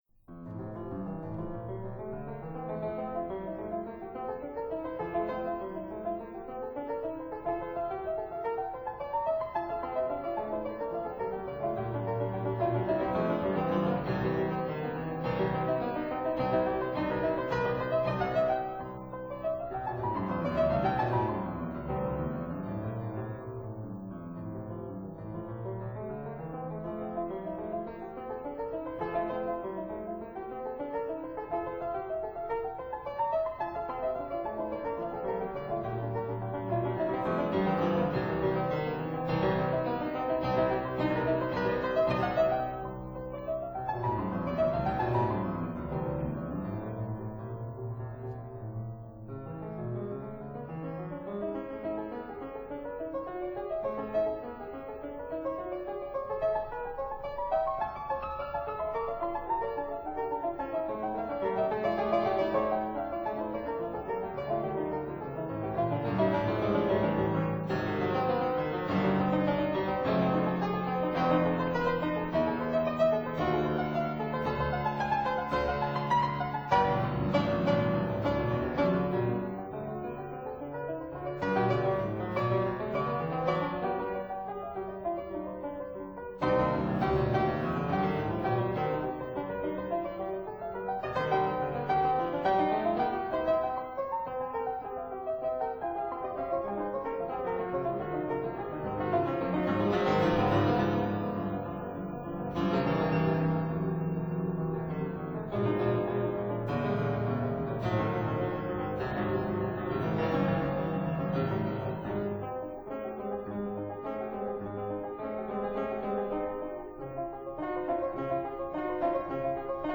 Erard Fortepiano